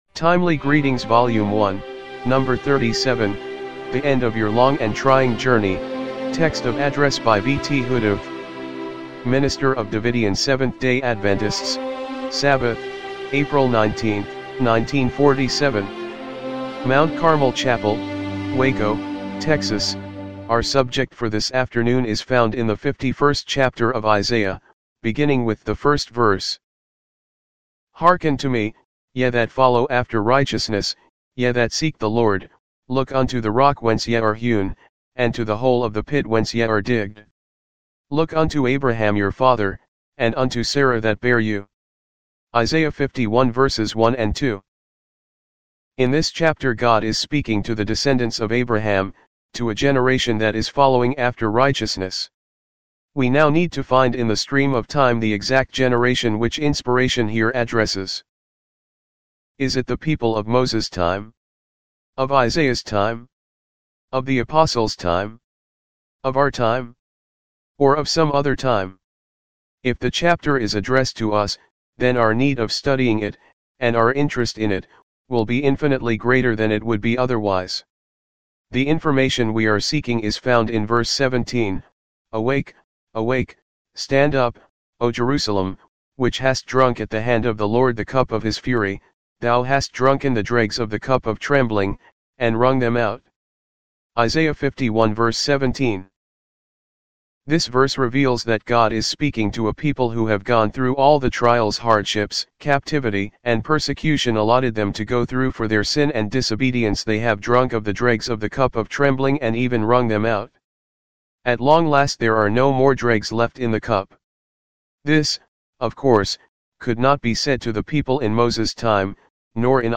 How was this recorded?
1947 MT. CARMEL CHAPEL WACO, TEXAS